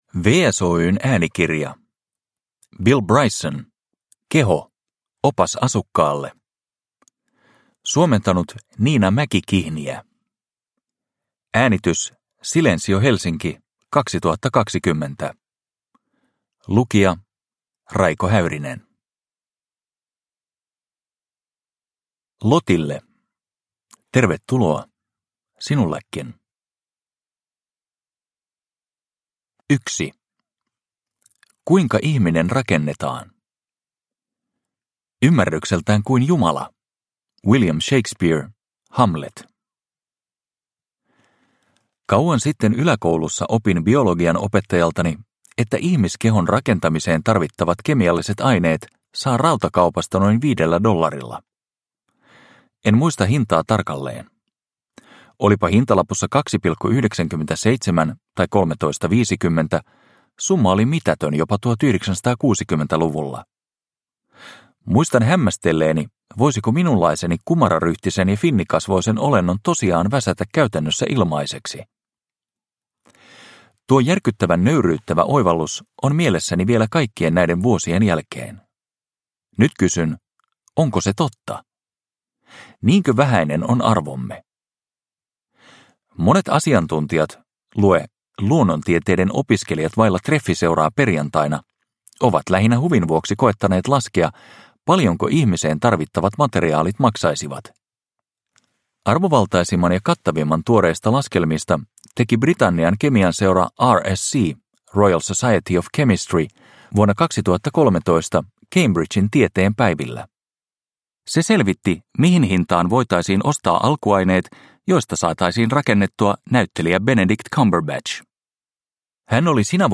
Keho – Ljudbok – Laddas ner